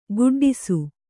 ♪ guḍḍisu